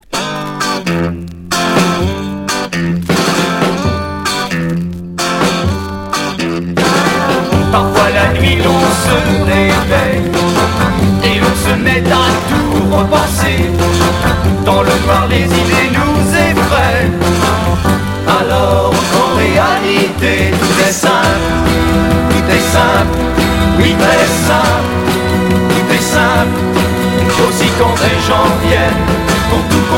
Beat rock